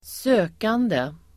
Uttal: [²s'ö:kande]